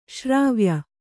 ♪ śrāvya